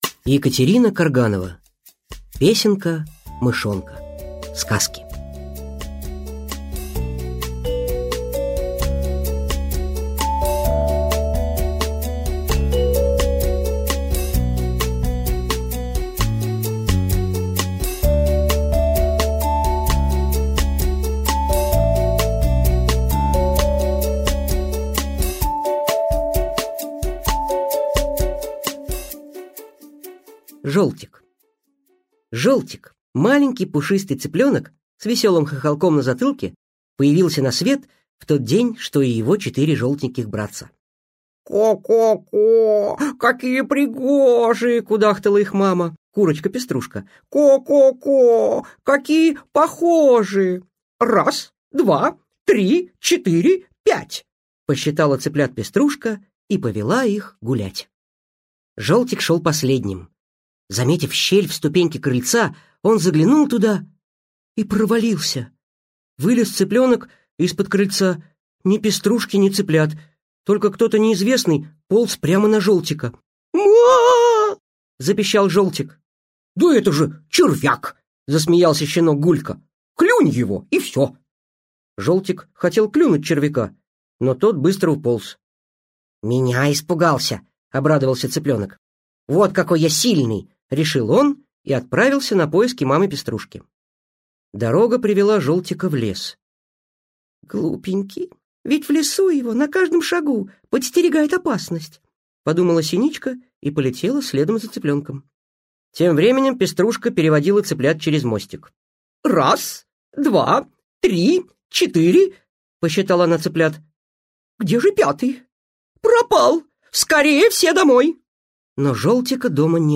Аудиокнига Песенка Мышонка. Сказки | Библиотека аудиокниг